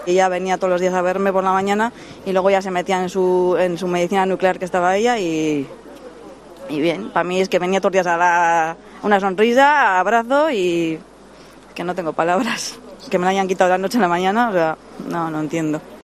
Muy emocionados
El acto, en el que algunos compañeros se han mostrado visiblemente emocionados, ha concluido con los aplausos de los participantes, y de hecho una compañera confesaba ante los medios de comuncicación "no tener palabras" y "no entender" lo ocurrido.